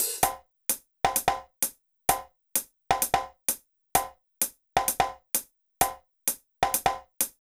BAL Beat - Mix 10.wav